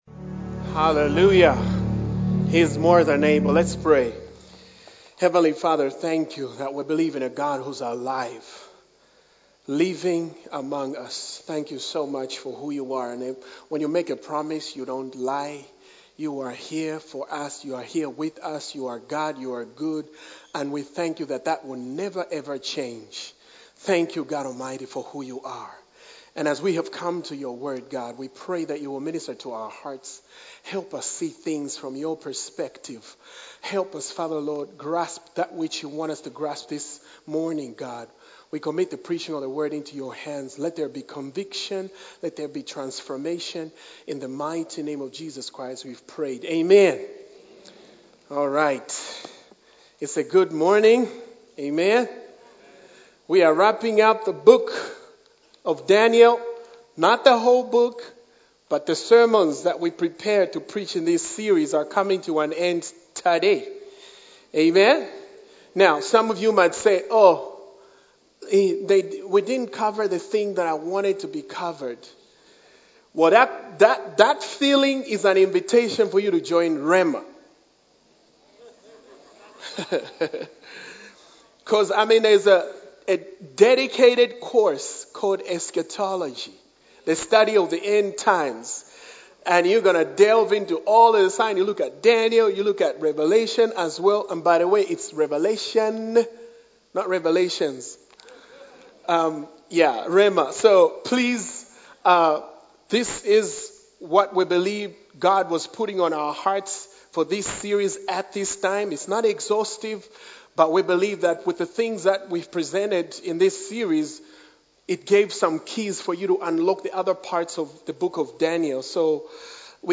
A message from the series "The Book of Daniel."